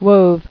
[wove]